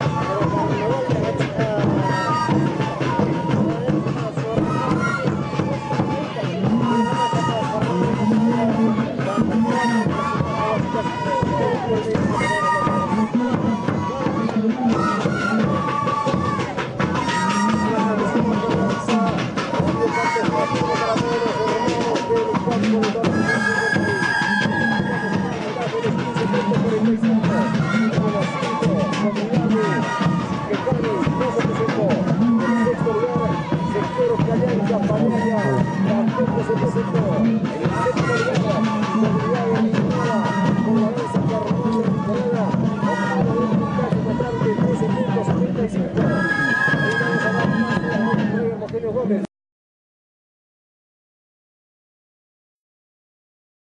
Por sus melancólicas notas esta clase de música/danza se supone que tiene un origen fúnebre.